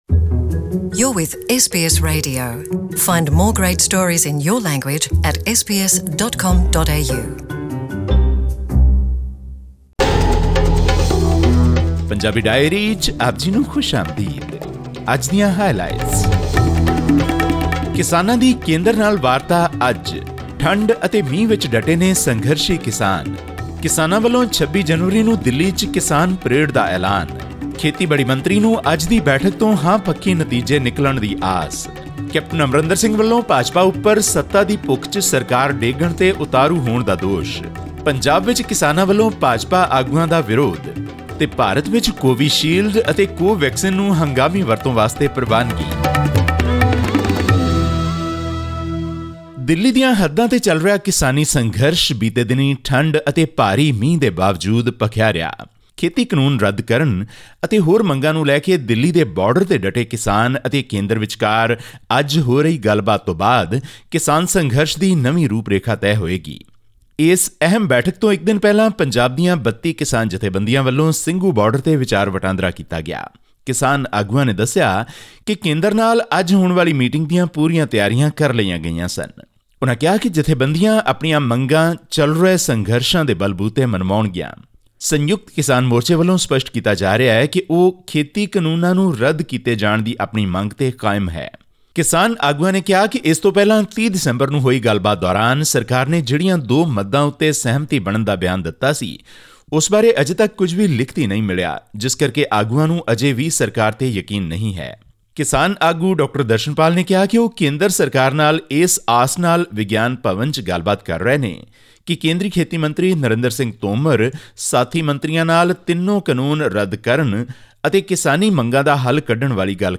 In this week’s news wrap from Punjab, listen in detail what India’s Agriculture Minister Narendra Singh Tomar had to say about the thousands of farmers protesting at Delhi’s border, just before their seventh round of talks.